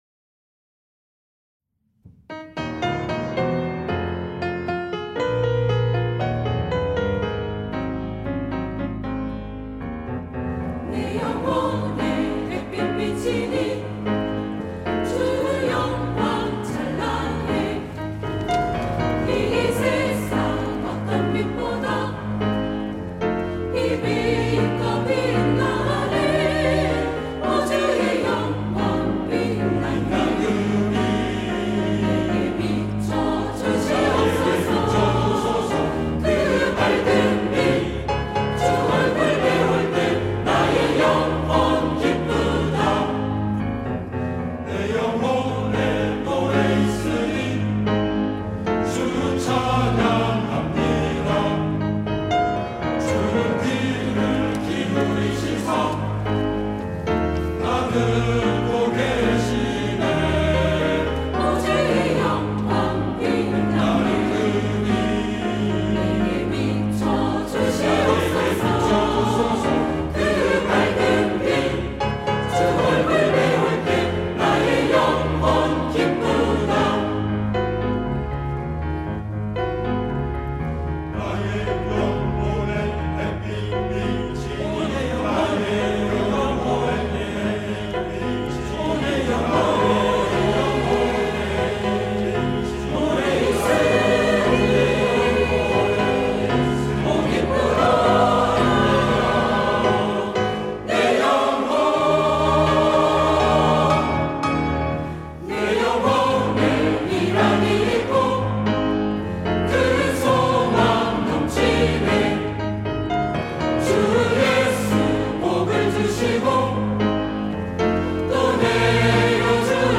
찬양대 시온